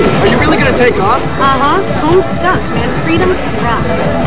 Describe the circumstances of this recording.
Sounds From the Generation X telefilm